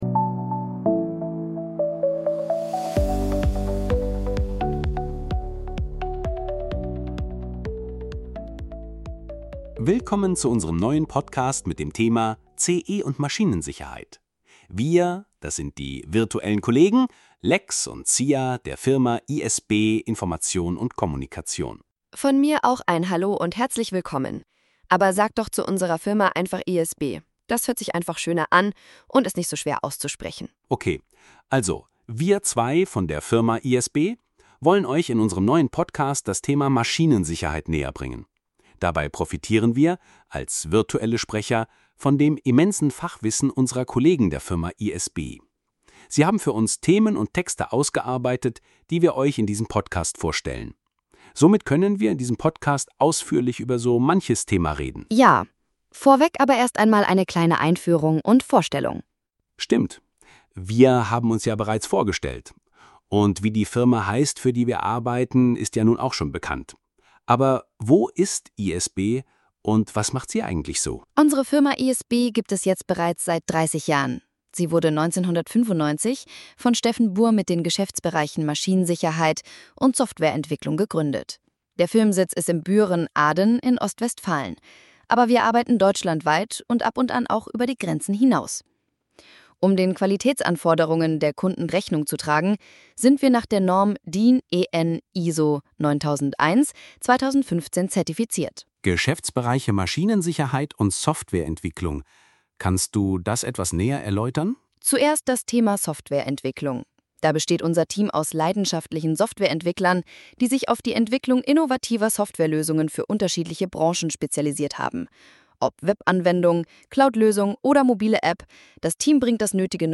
Das Fundament bilden unsere zwei Arbeitsbereiche: Software-Entwicklung und Maschinensicherheit CE. Die Inhalte werden von den Fachkollegen erarbeitet und die virutellen Kollegen Sia und Lex sprechen diese für uns ein.